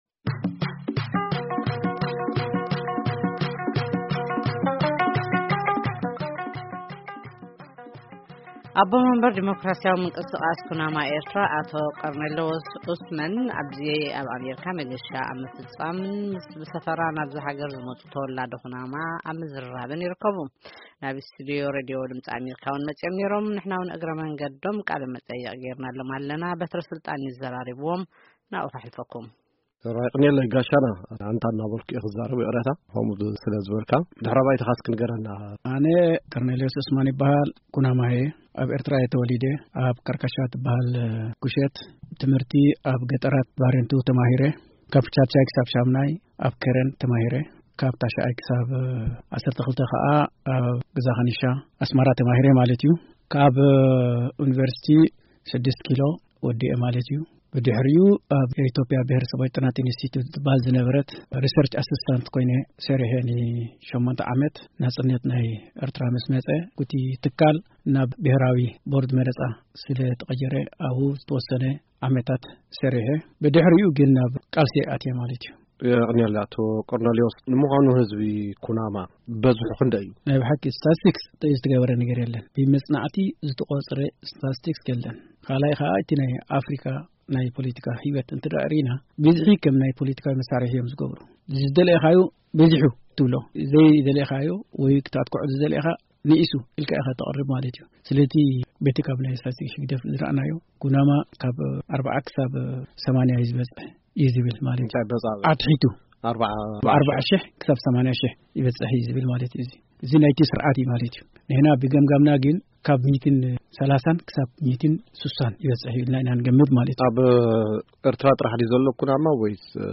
1ይ ክፋል ቃለ-መጠይቅ